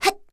assassin_w_voc_motion_a.ogg